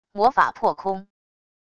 魔法破空wav音频